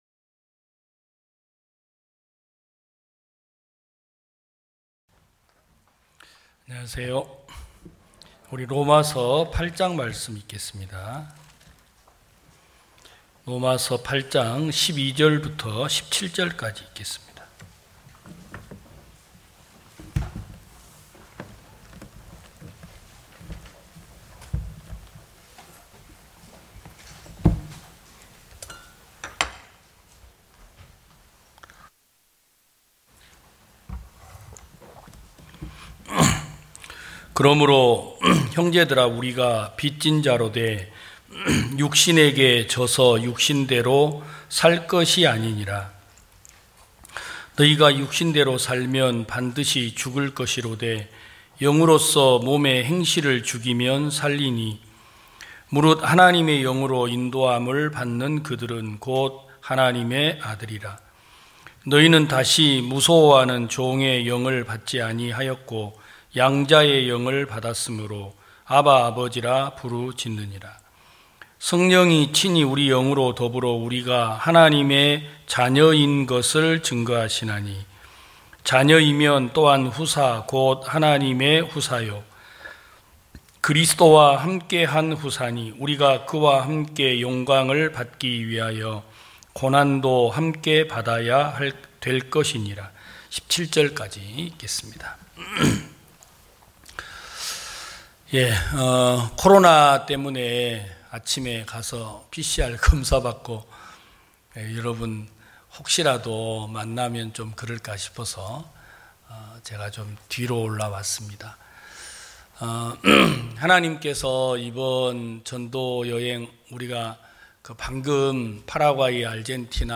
2022년 06월 12일 기쁜소식부산대연교회 주일오전예배
성도들이 모두 교회에 모여 말씀을 듣는 주일 예배의 설교는, 한 주간 우리 마음을 채웠던 생각을 내려두고 하나님의 말씀으로 가득 채우는 시간입니다.